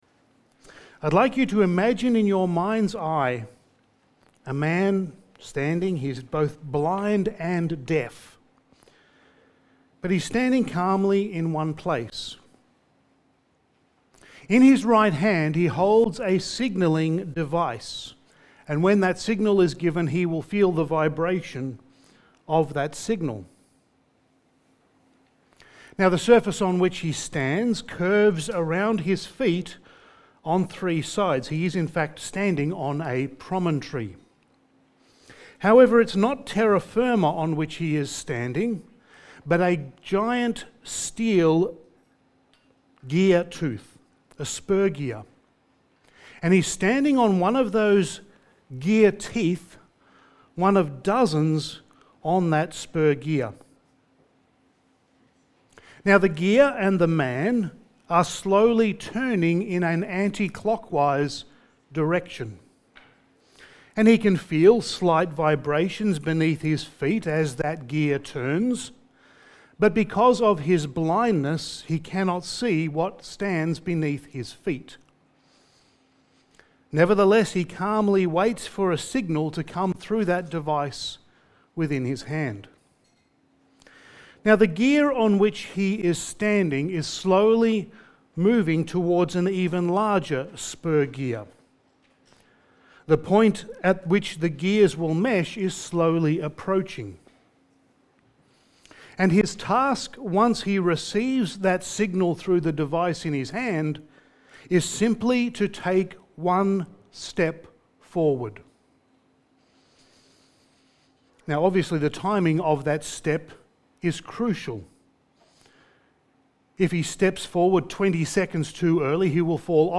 Passage: Daniel 5:13-31 Service Type: Sunday Morning